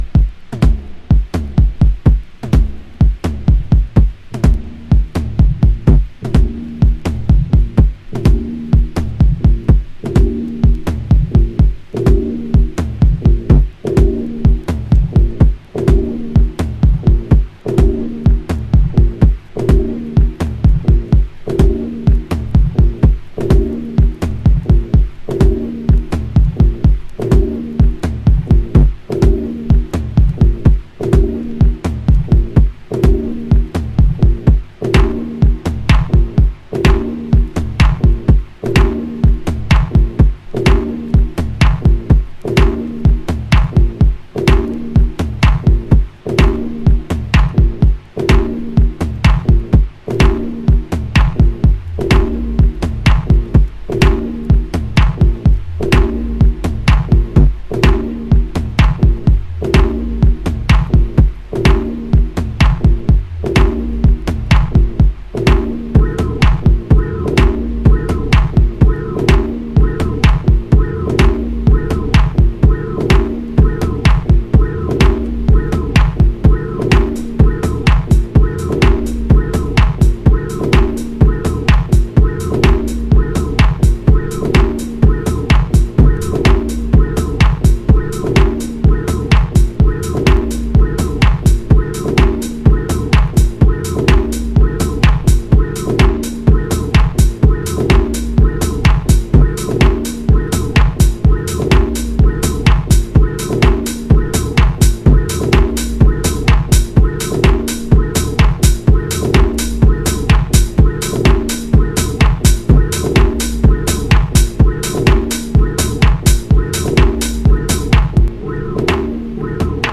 アナログに拘りぬいて90'sを昇華したマシーナリーテクノ。荒い粒子が飛び散る音像がこの方達の持ち味ですね。